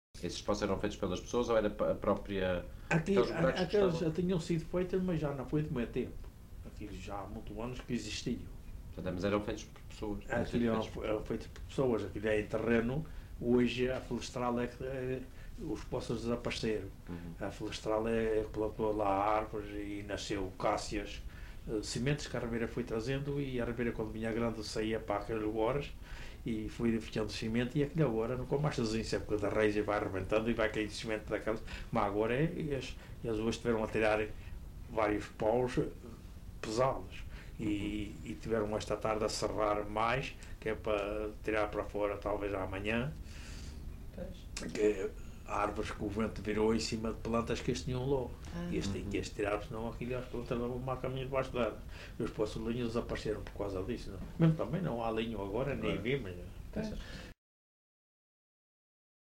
LocalidadeRibeira Seca (Calheta, Angra do Heroísmo)